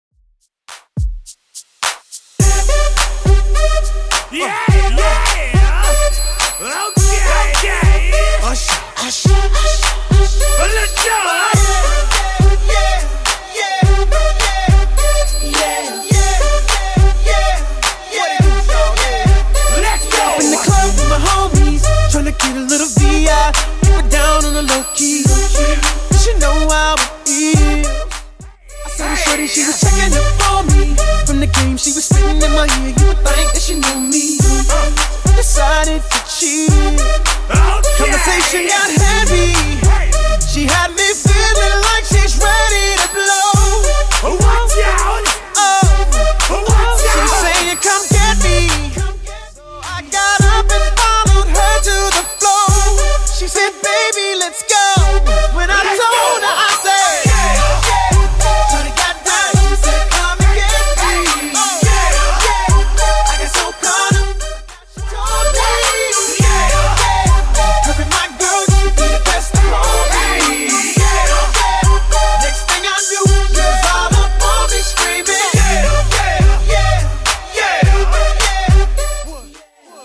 Genre: 80's
BPM: 110